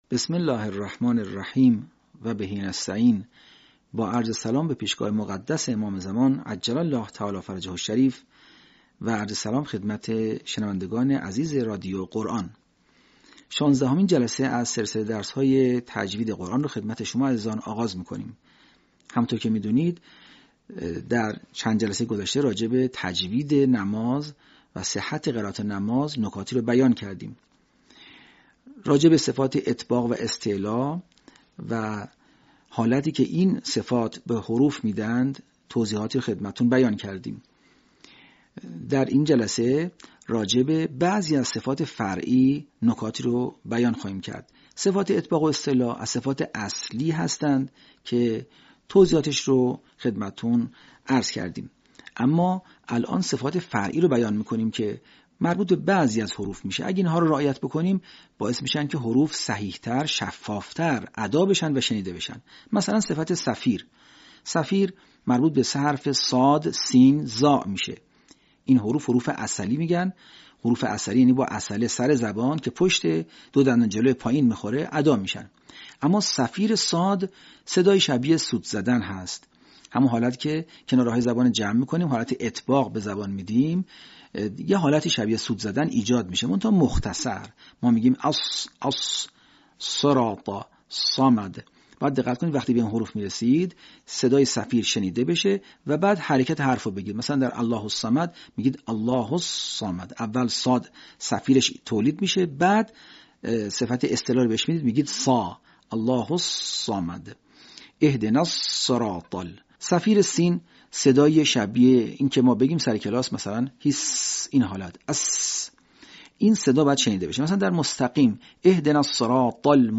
صوت | صفت صفیر در علم تجوید